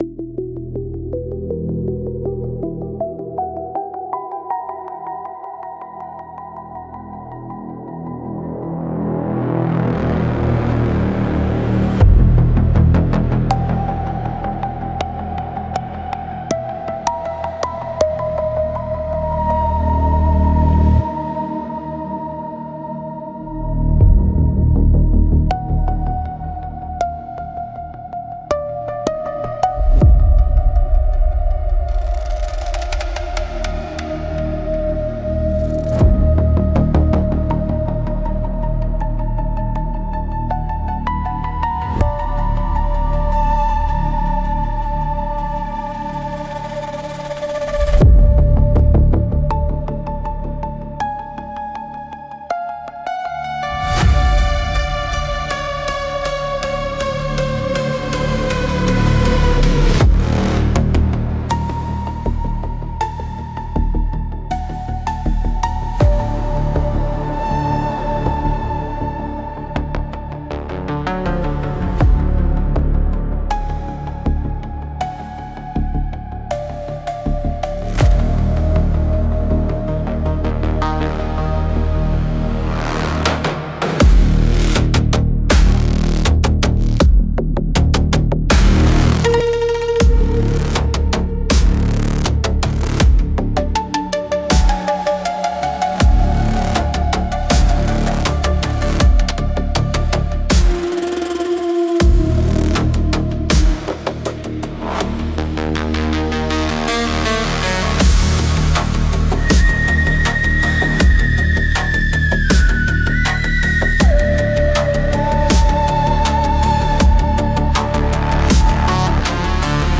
Instrumentals - The Long, Cold Process